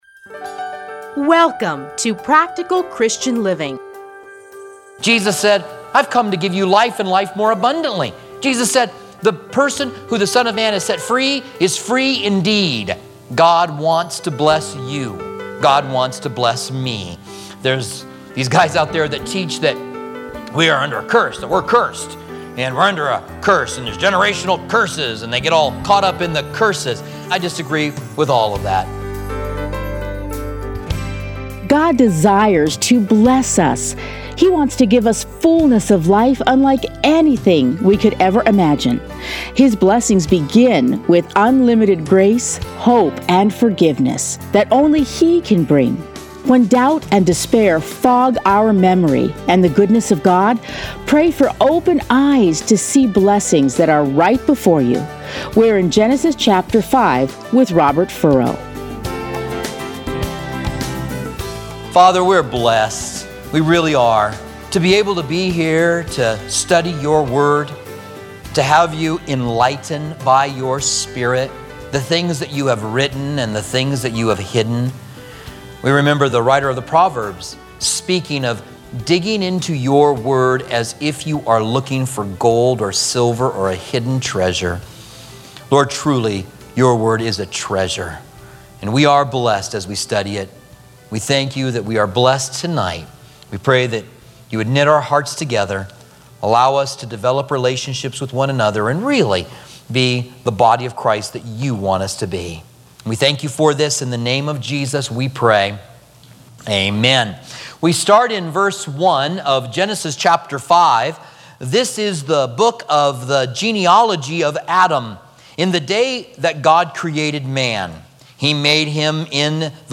Listen here to a teaching from Genesis.